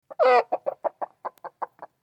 Sound Effects
Chicken Noise Sound